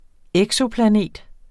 Udtale [ ˈεgsoplaˌneˀd ]